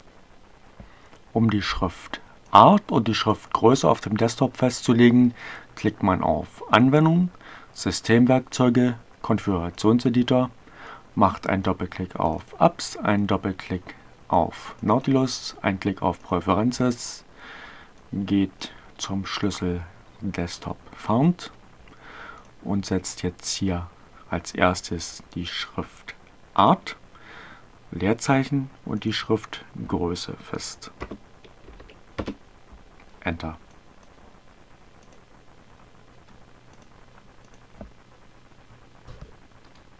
Tags: Anfänger, CC by-sa, Fedora Core, gconf, gconf-editor, Gnome, Linux, Ogg Theora, ohne Musik, screencast, short